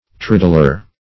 triddler - definition of triddler - synonyms, pronunciation, spelling from Free Dictionary Search Result for " triddler" : The Collaborative International Dictionary of English v.0.48: Triddler \Trid"dler\, n. (Zool.)